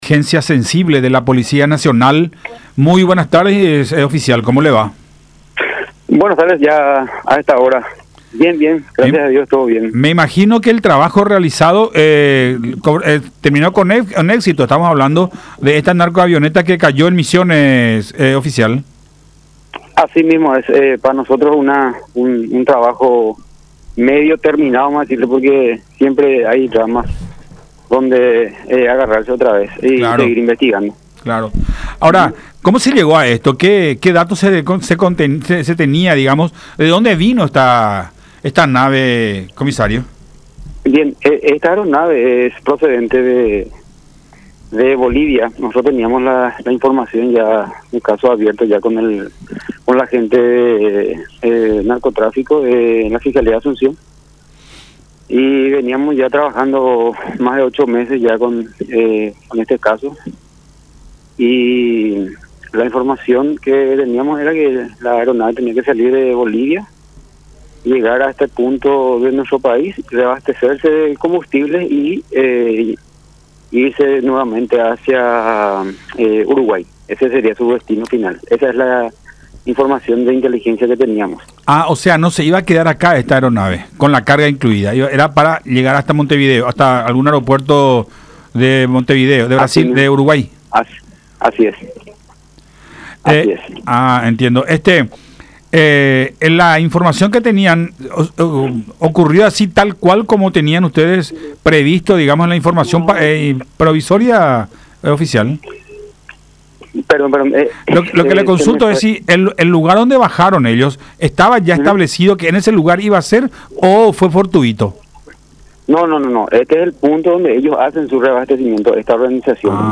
en conversación con Buenas Tardes La Unión por Unión TV y radio La Unión.